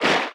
Sfx_creature_babypenguin_swim_fast_05.ogg